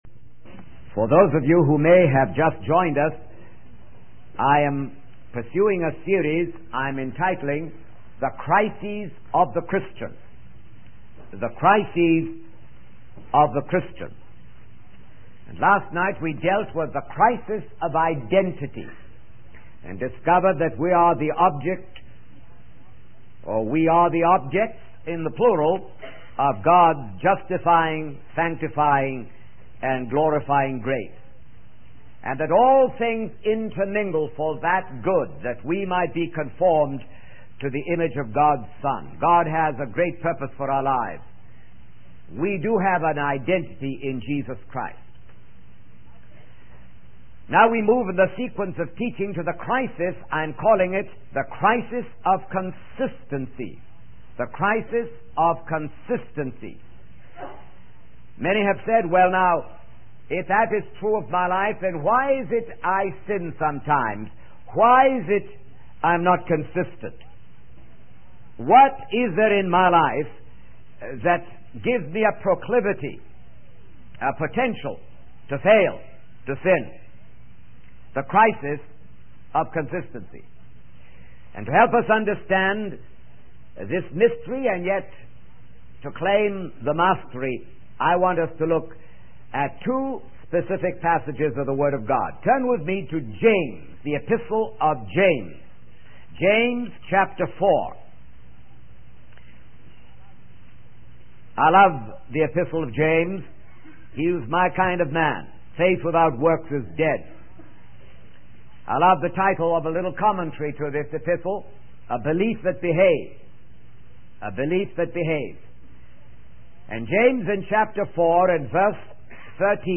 In this sermon, the preacher focuses on the crisis of consistency in the lives of believers. He begins by referencing James 4:13-17, where James warns against making plans without acknowledging God's will. The preacher emphasizes the importance of having a belief that behaves, meaning that faith should be accompanied by actions.